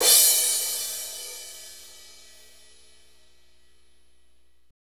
Index of /90_sSampleCDs/Northstar - Drumscapes Roland/DRM_Motown/KIT_Motown Kit2x
CYM R B CR0J.wav